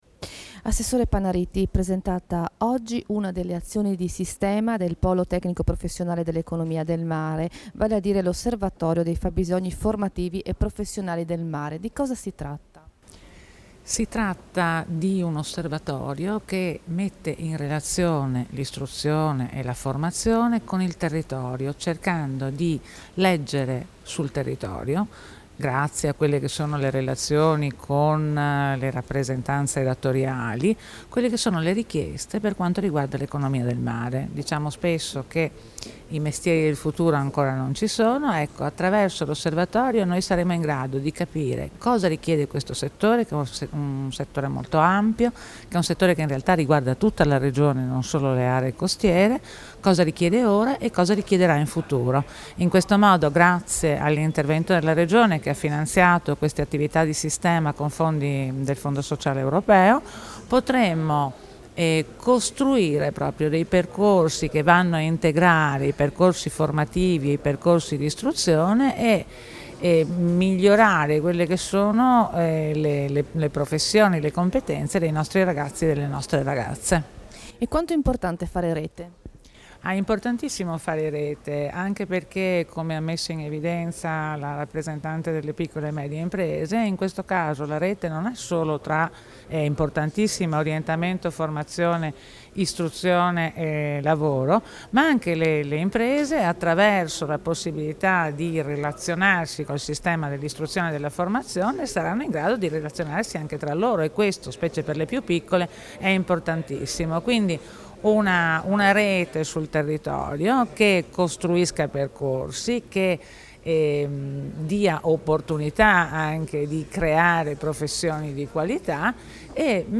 Dichiarazioni di Loredana Panariti (Formato MP3)
a margine della presentazione dell'osservatorio sui fabbisogni formativi e professionali del comparto economico del mare, rilasciate a Trieste il 9 novembre 2017